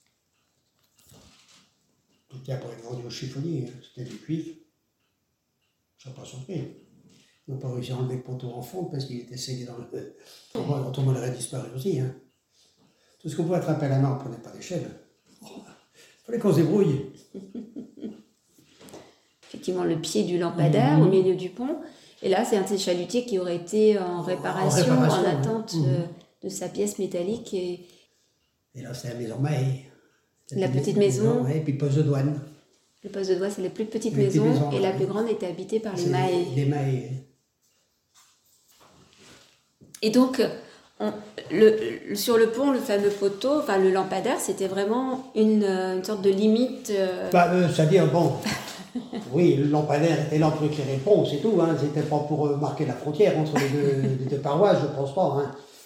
Témoignages audio